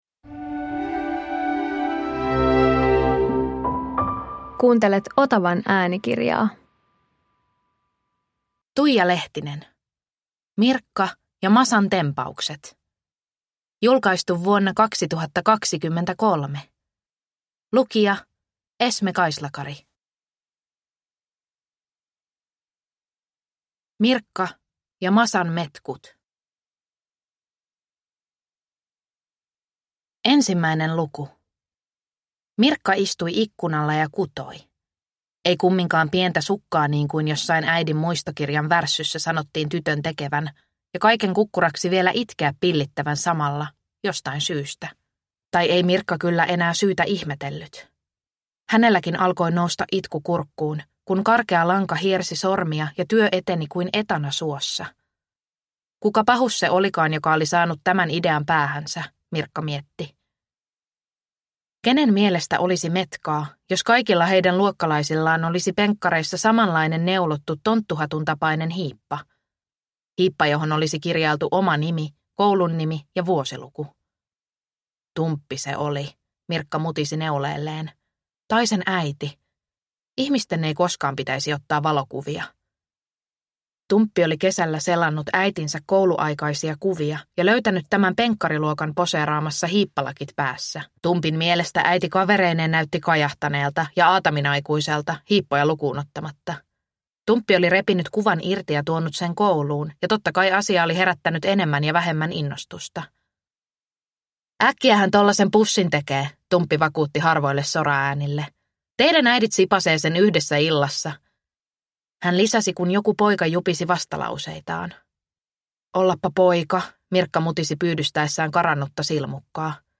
Mirkka ja Masan tempaukset – Ljudbok – Laddas ner